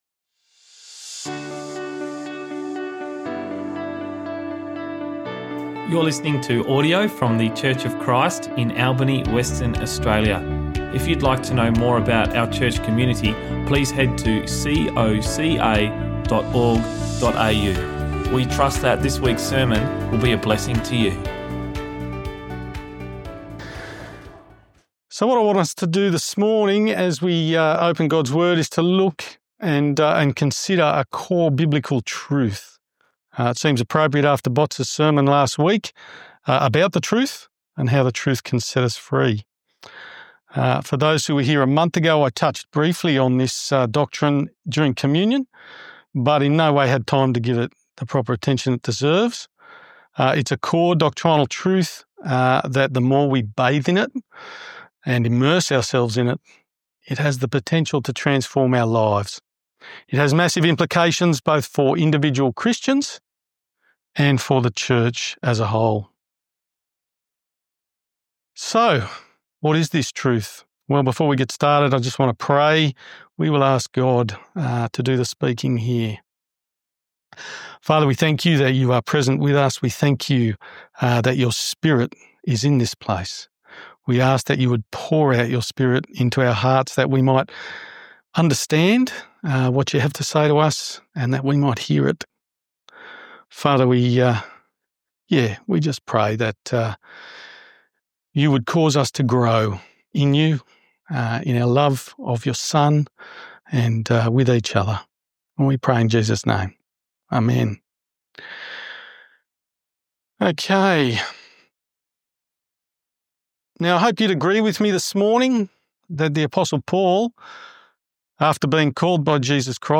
Sermons | Church of Christ Albany